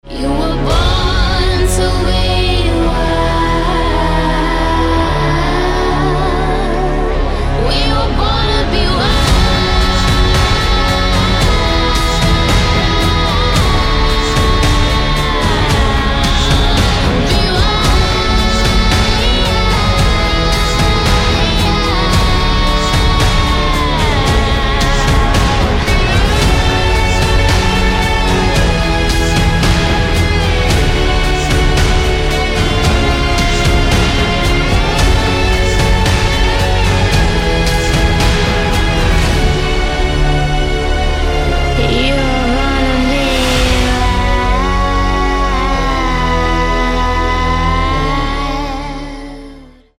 • Качество: 320, Stereo
саундтрек
Музыка из трейлера